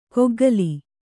♪ koggali